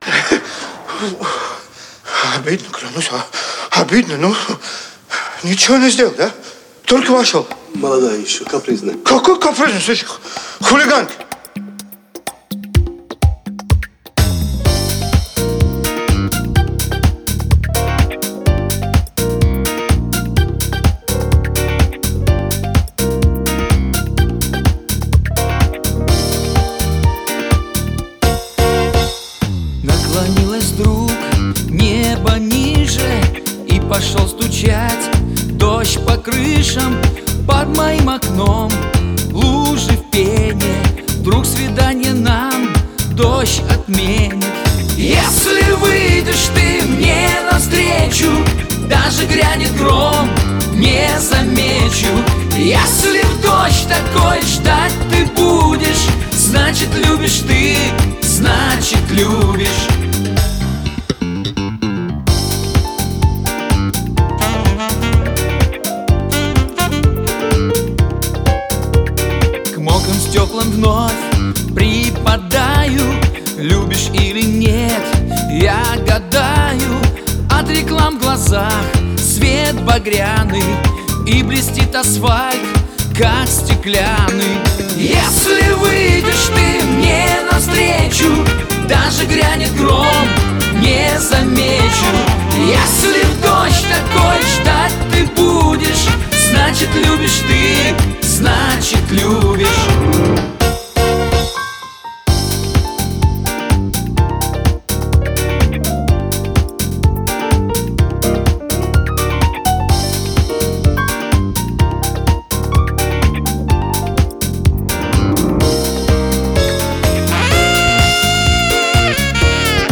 вокал, гитара
вокал, бас-гитара, клавишные
альт-саксофон, клавишные
ударные, перкуссия